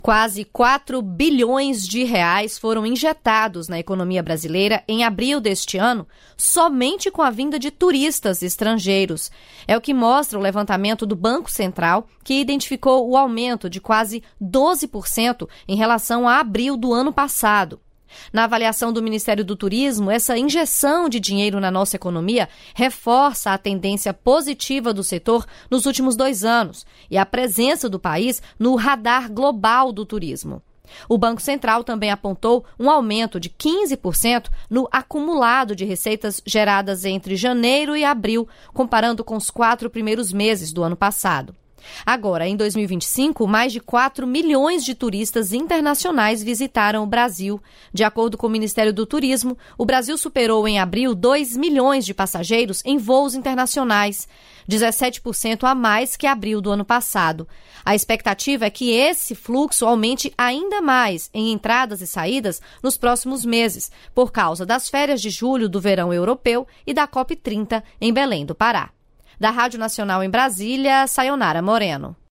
A Secretaria Estadual de Transportes do Rio de Janeiro assinou, nesta quinta-feira, termo de cooperação com seis municípios da Baixada Fluminense para a elaboração de seus planos de mobilidade urbana. O evento foi realizado em Nova Iguaçu, que é um dos municípios contemplados pelo convênio.
O secretário estadual de transporte, Carlos Roberto Osório, explica como se dará o apoio do governo do estado aos municípios.